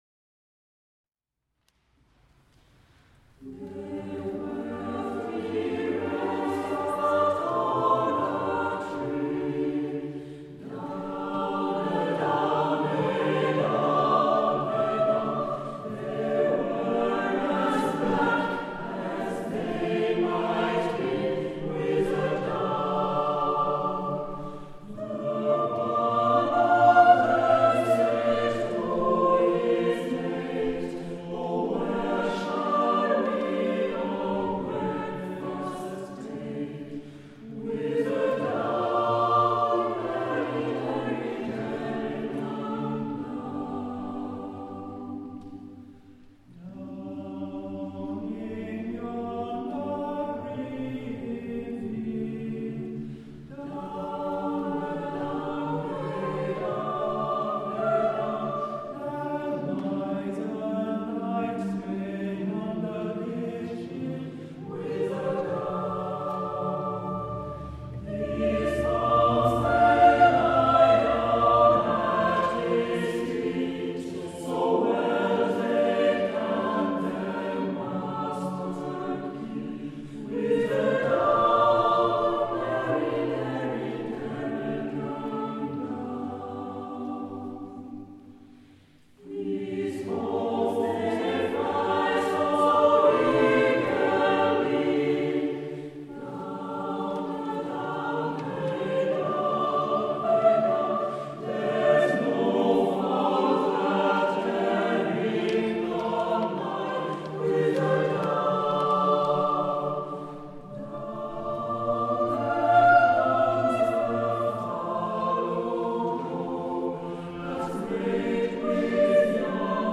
- Dimanche 28 avril 2013 à 17h00, au temple de Coppet, VD, Suisse